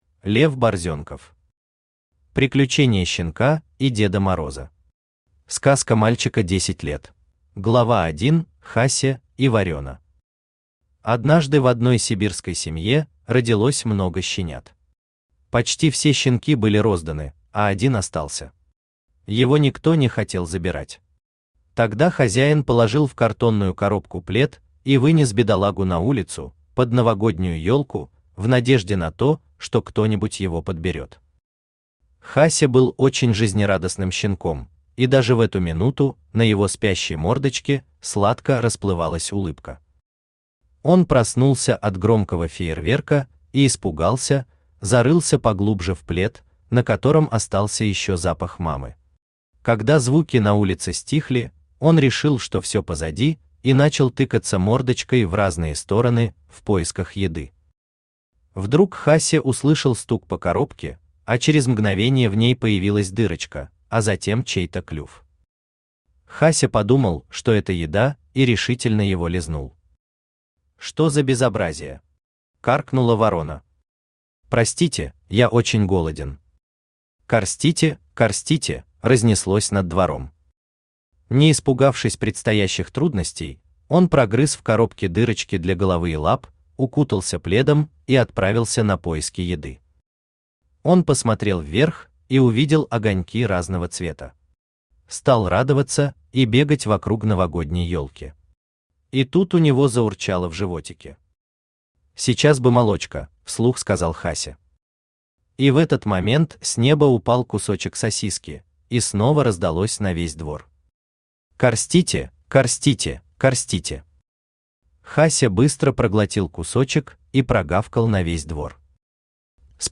Аудиокнига Приключение щенка и Деда Мороза.
Сказка мальчика 10 лет Автор Лев Алексеевич Борзенков Читает аудиокнигу Авточтец ЛитРес.